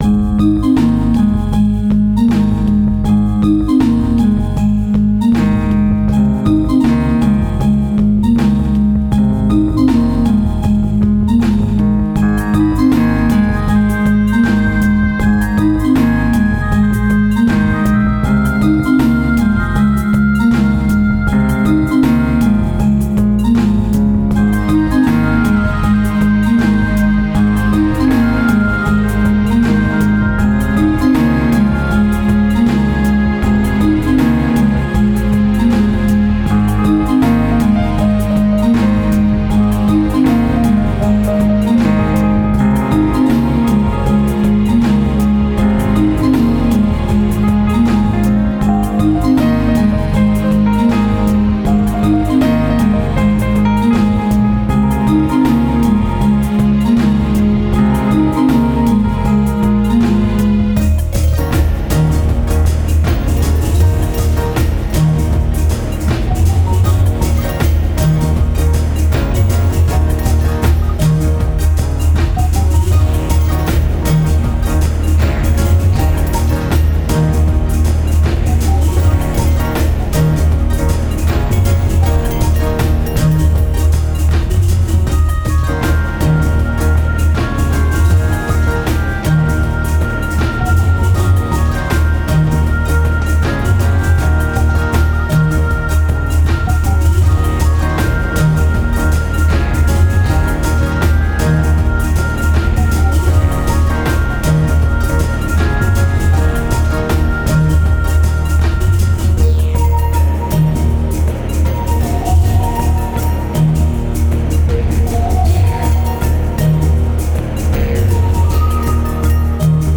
Elecrronic ambient sci-fi theme